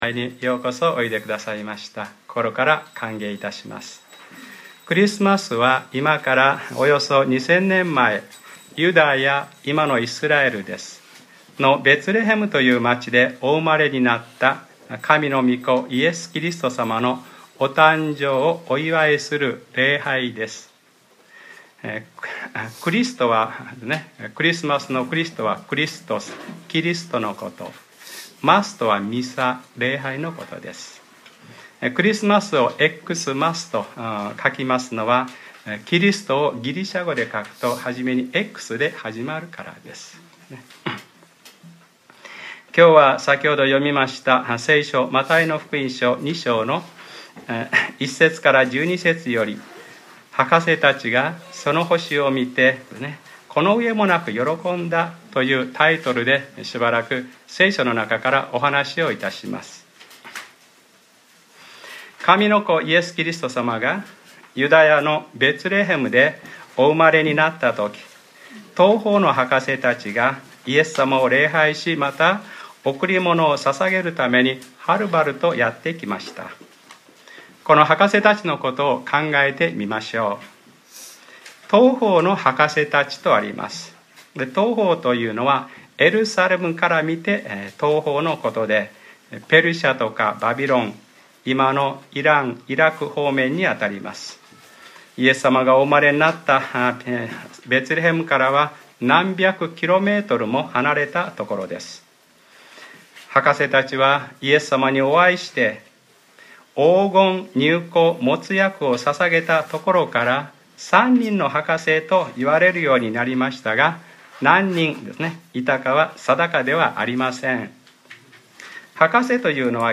2014年12月21日）礼拝説教 『その星を見て博士たちはこの上もなく喜んだ』